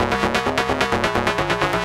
CS_FMArp C_130-C.wav